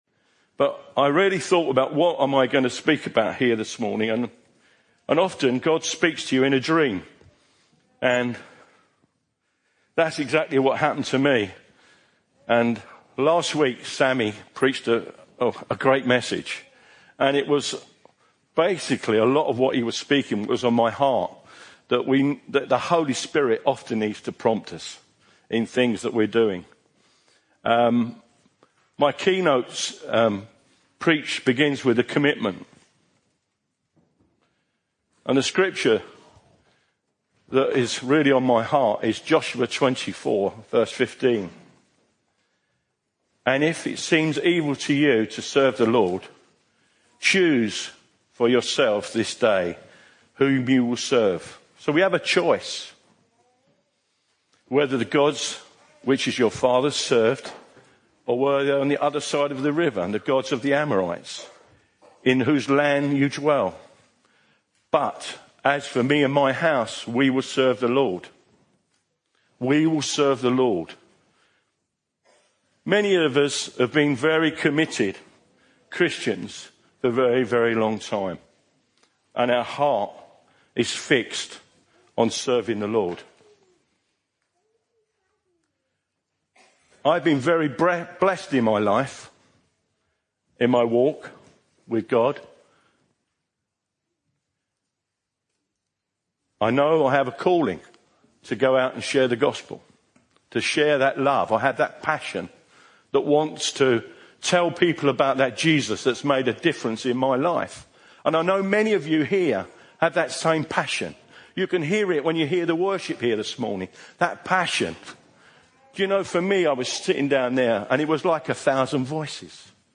I was invited to speak at my home church Trinity in Brentwood.